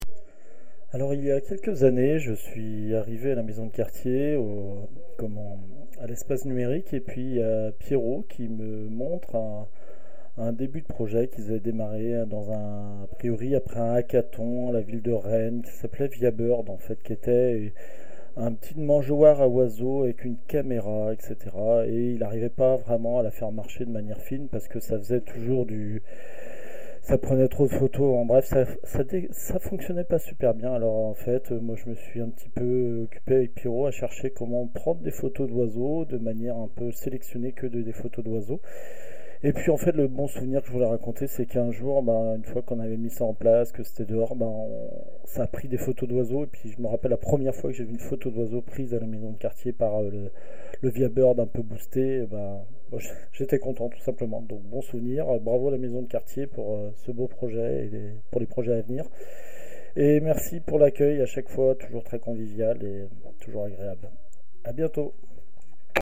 Cabine de témoignages